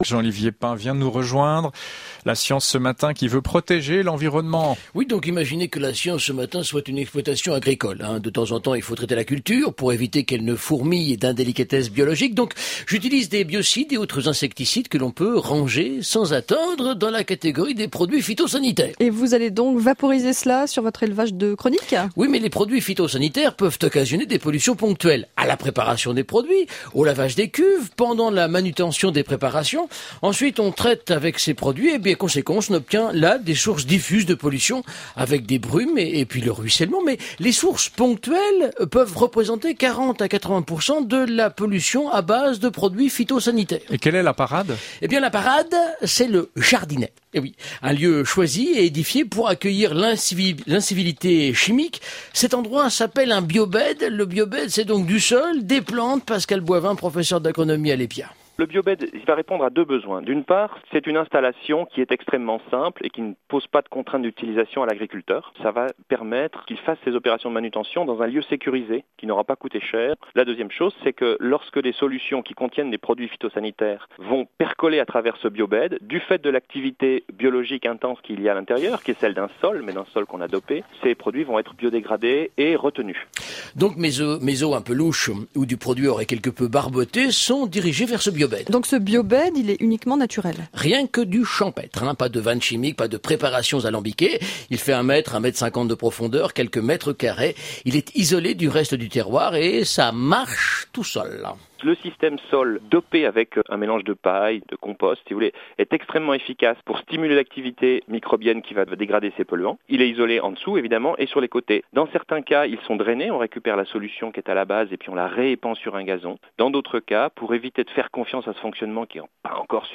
Biobed : une solution pour dépolluer les effluents (Radio Suisse Romande)
La science de Pain, émission scientifique de la Radio Suisse Romande, est décidément une mine de pédagogie.